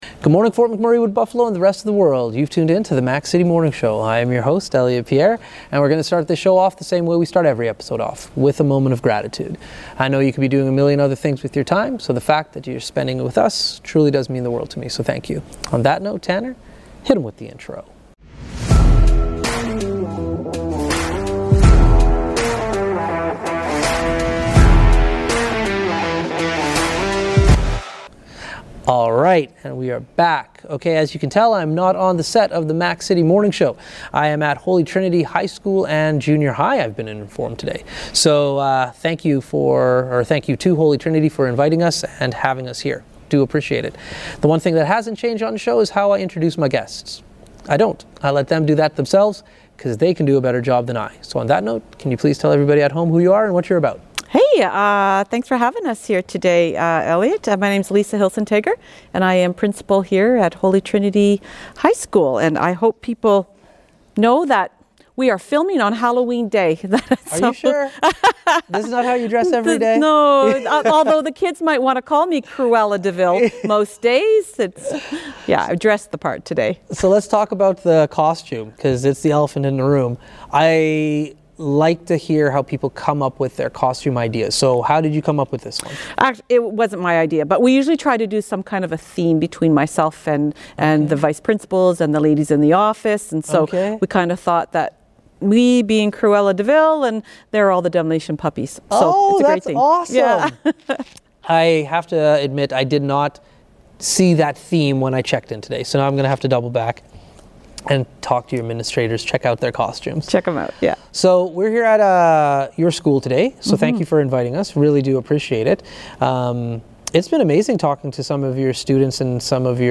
This episode was pre-recorded back on Halloween, you have to tune in to hear and see the costume choices!